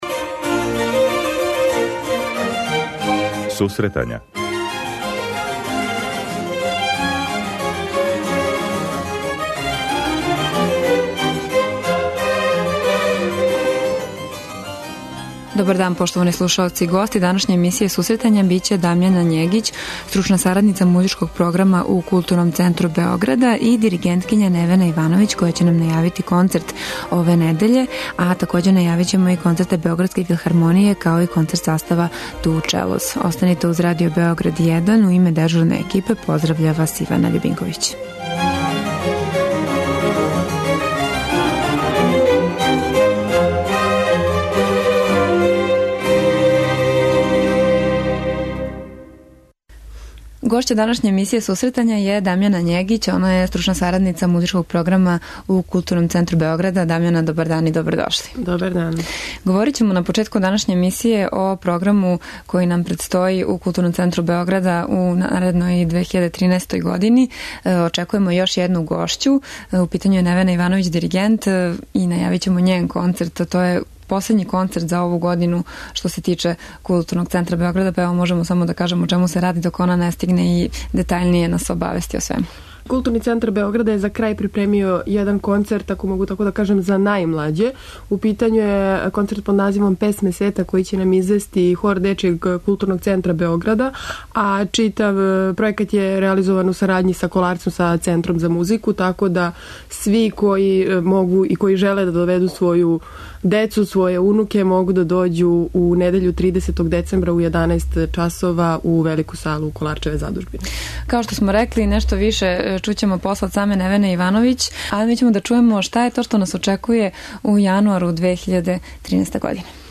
преузми : 26.25 MB Сусретања Autor: Музичка редакција Емисија за оне који воле уметничку музику.